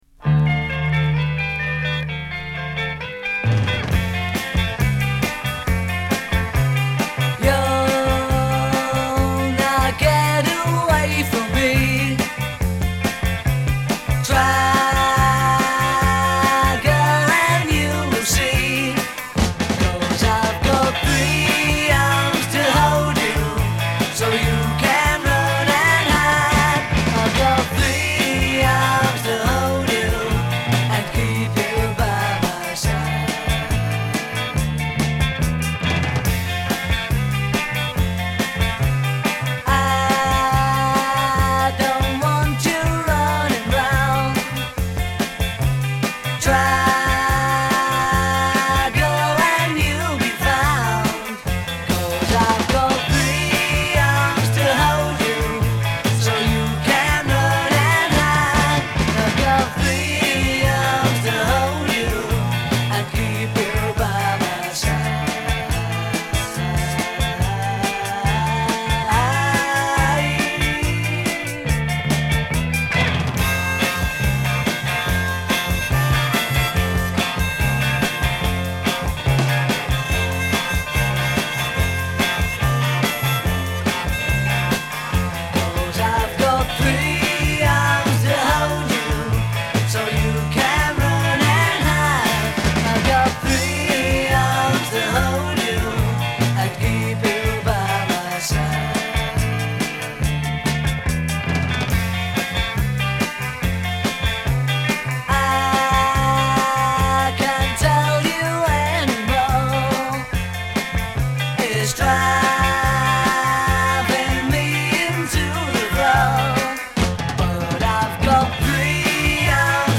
Genre: Pop, Rock
Style: Power Pop